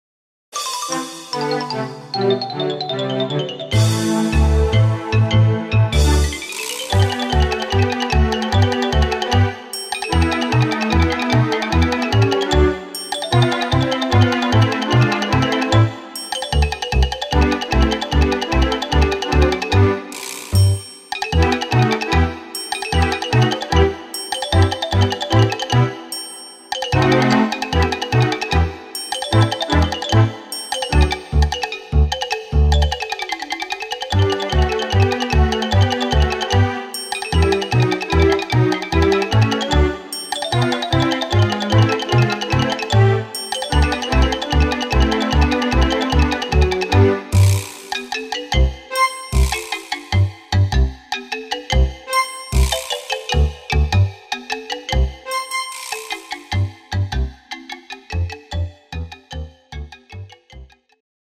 instr. Marimba